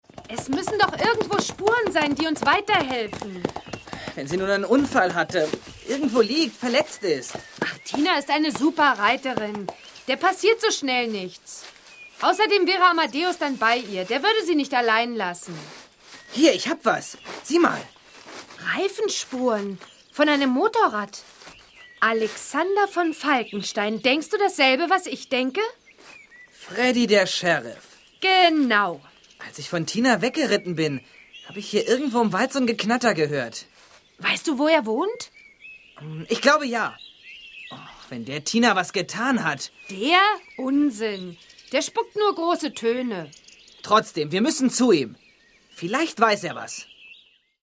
Ravensburger Bibi und Tina - Tina in Gefahr ✔ tiptoi® Hörbuch ab 6 Jahren ✔ Jetzt online herunterladen!